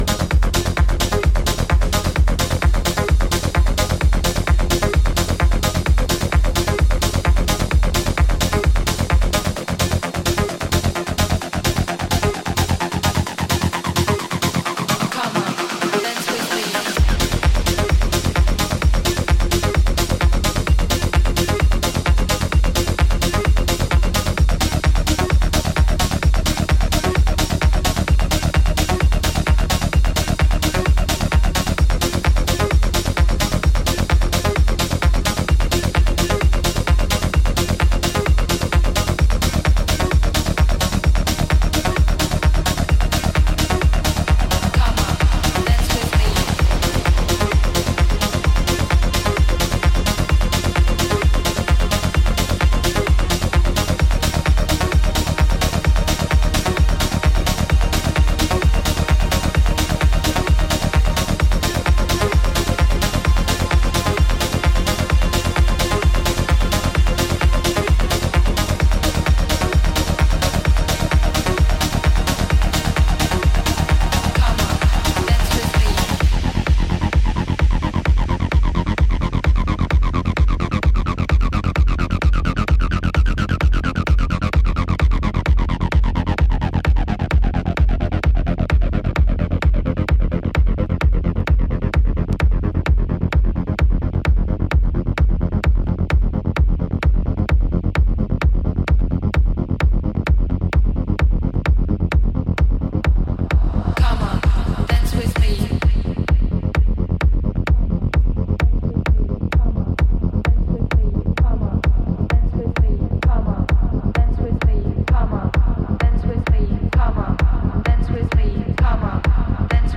EBM/Industrial, Techno, Trance